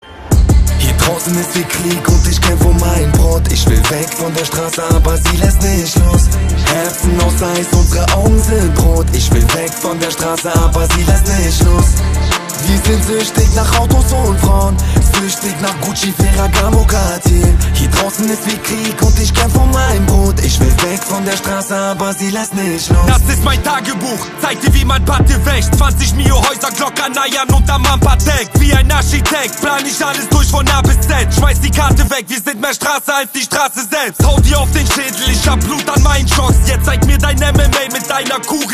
Kategorien Rap/Hip Hop